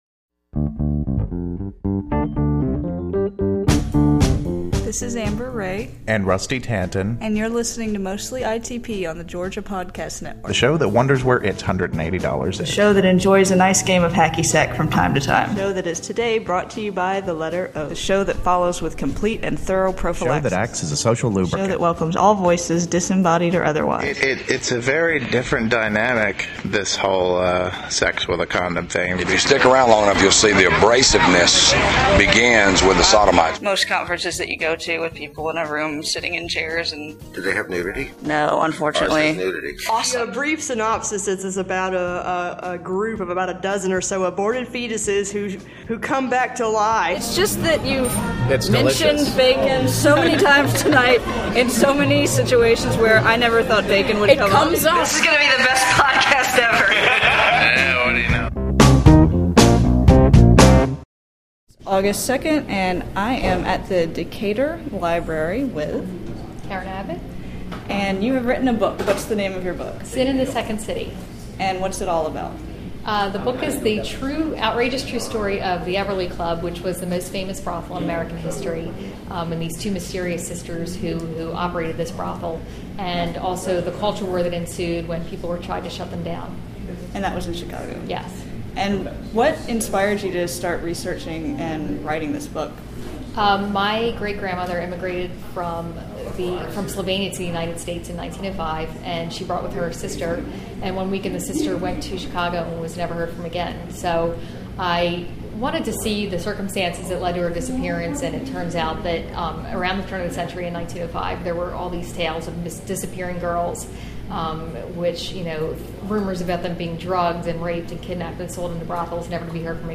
Interview
We recorded this interview after the reading and book-signing. Sin in the Second City is chock full of interesting history; and as it turns out, America's past is a whole lot like its present where sex - especially sex for money - is concerned.